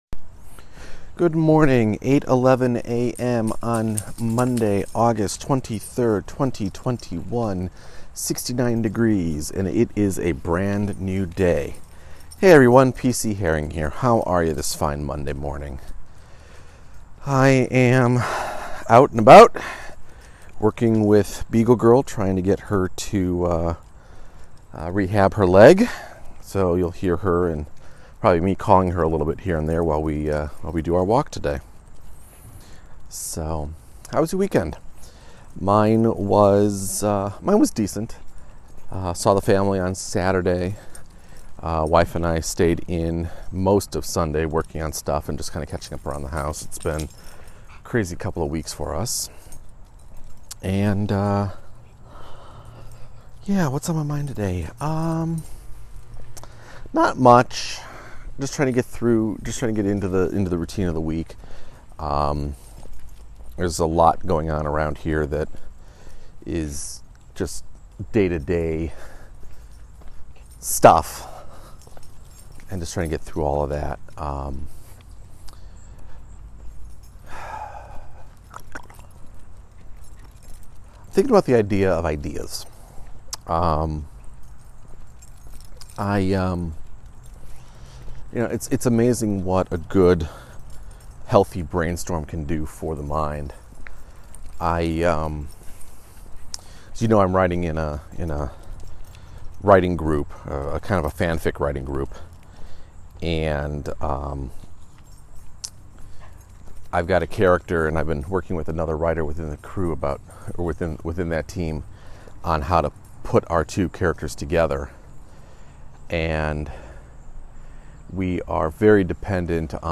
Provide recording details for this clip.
A shorter recording within a much longer walk as I work with rehabbing Beagle Girl. I talk about how some brainstorms come very quickly and others take their sweet time…others don’t happen at all.